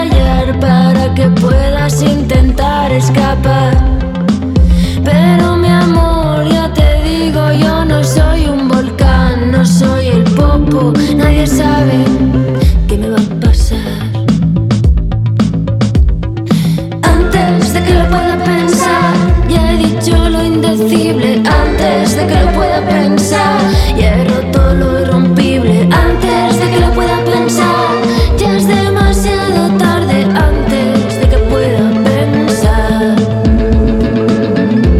# Latin